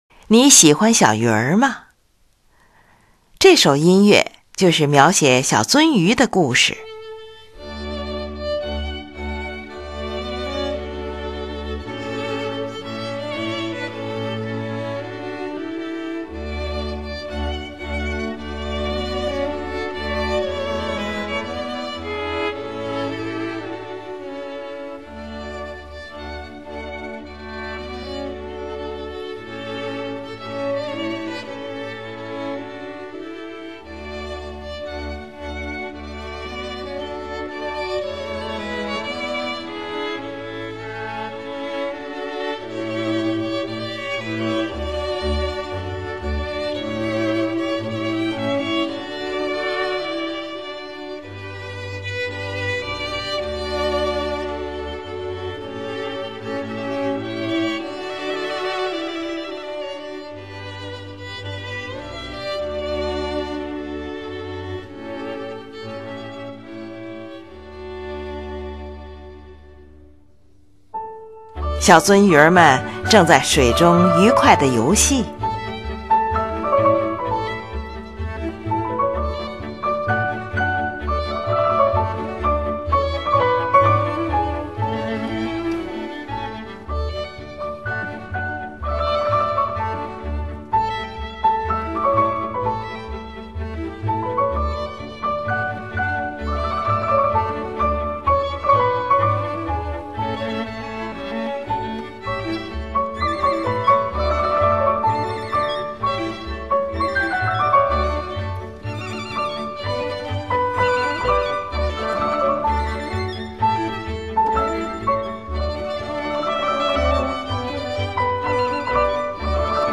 音乐明快而轻柔。
（第二变奏）中提琴奏主题，大提琴以同样的主题伴奏旋律，钢琴像回声一样与它们形成呼应。
整个第四变奏都是在这种情绪的强烈对比中度过的。